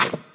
step-2.wav